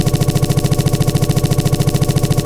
engineDrive.wav